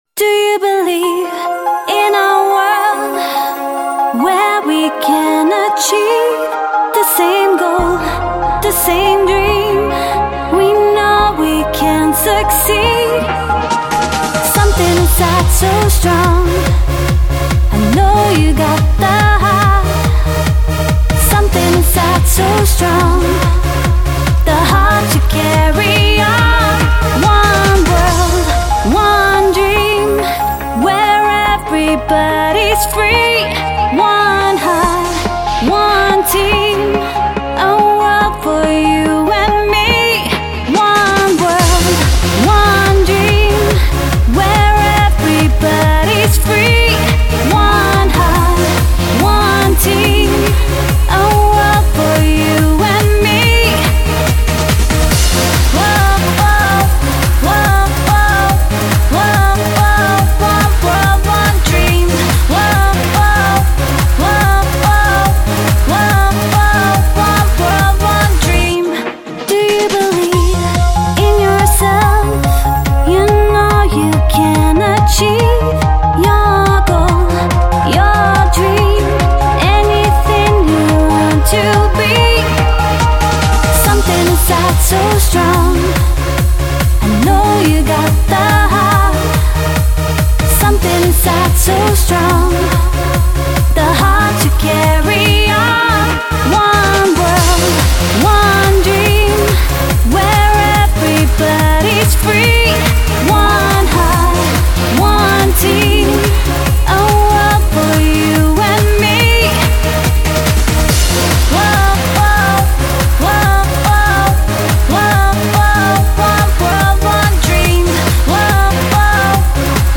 Жанр:Super/Club/Dance